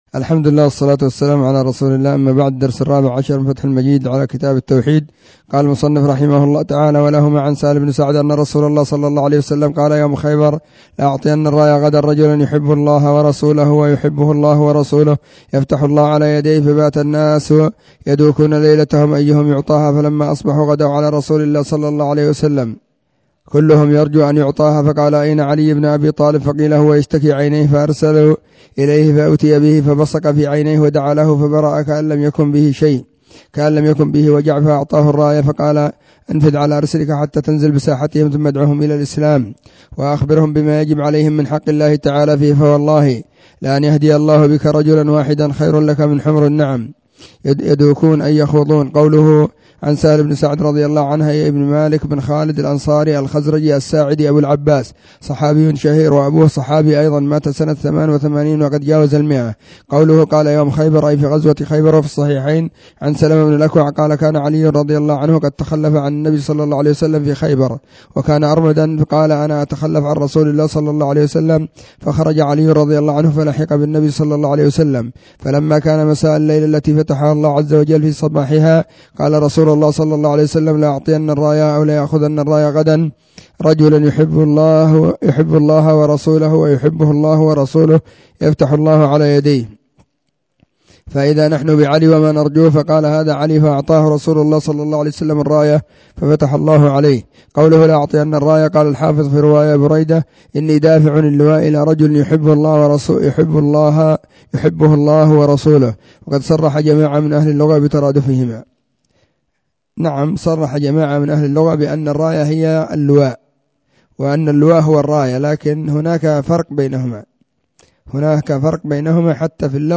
📢 مسجد الصحابة – بالغيضة – المهرة، اليمن حرسها الله.
فتح_المجيد_شرح_كتاب_التوحيد_الدرس_14.mp3